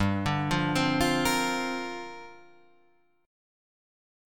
Gm11 Chord